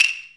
CLAP - SCARED.wav